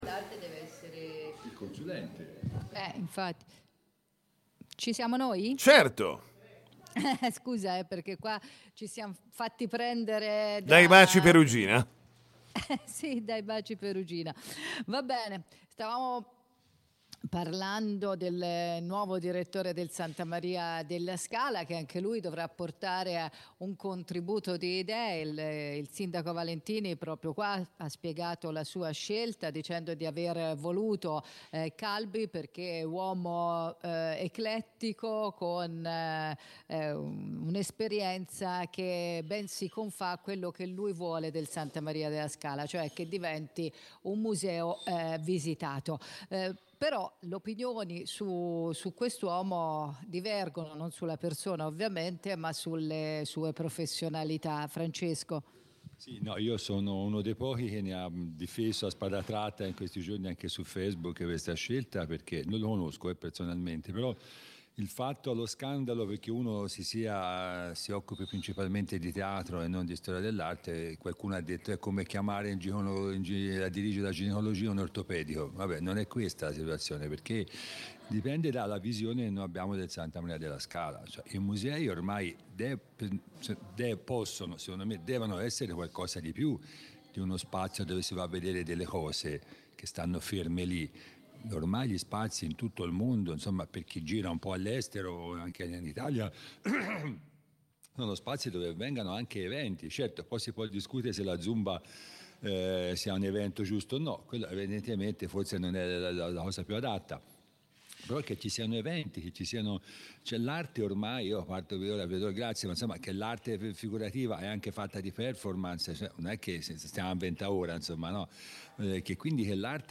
In diretta dal New York Cafè